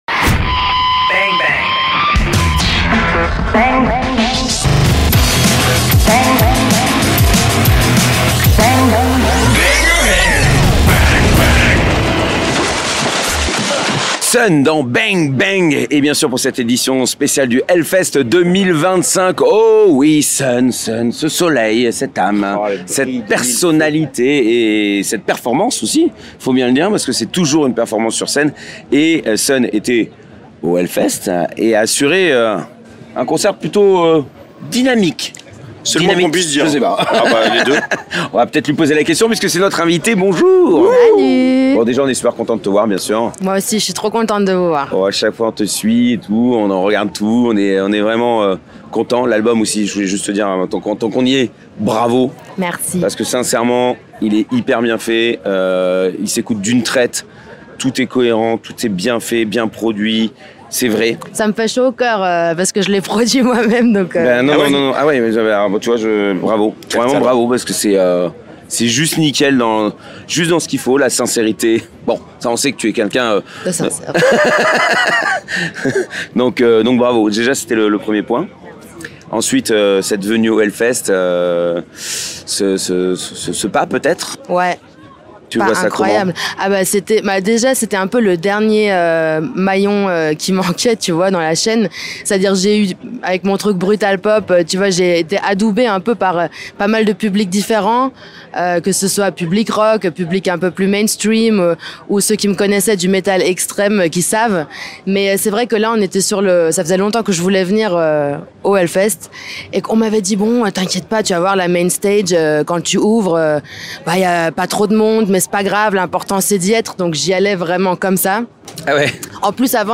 Voilà encore une édition de HELLFEST accomplie ! Et nous avons fait 23 interviews !